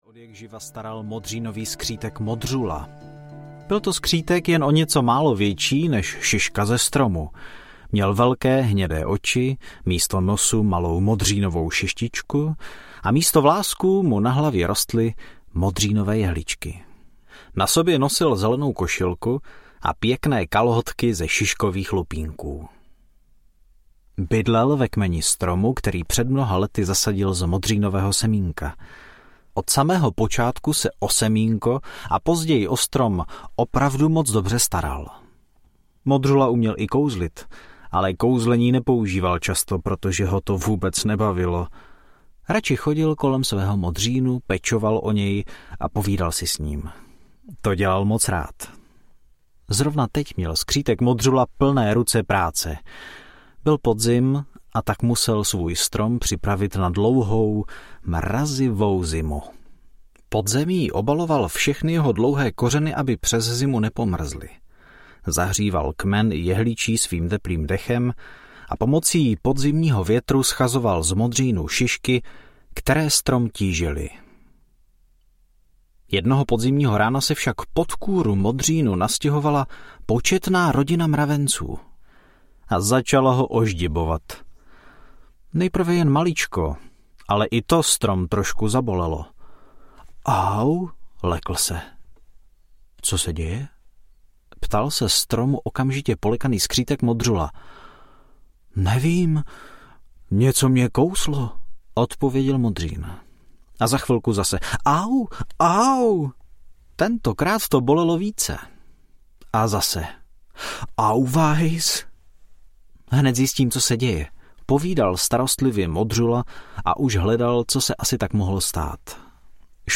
Pohádky z lesa audiokniha
Ukázka z knihy